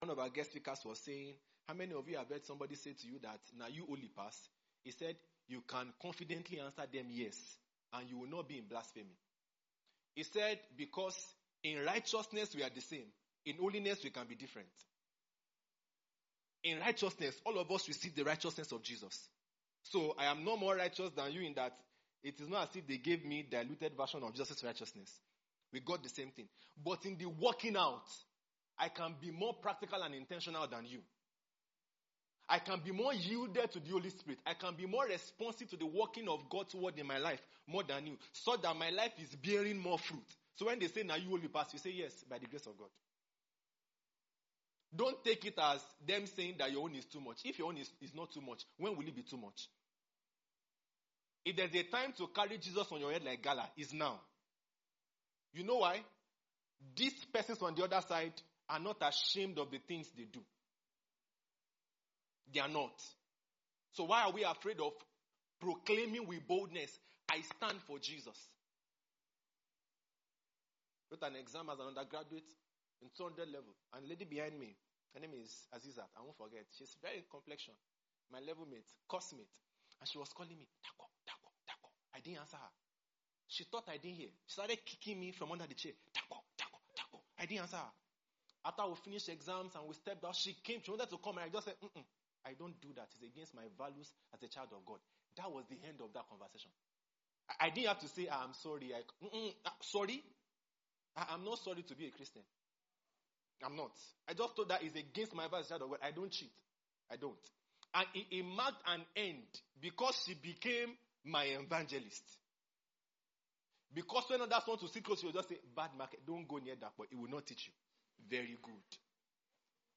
IRC PHC 2025 DAY 1 EXHORTATION - Voice of Truth Ministries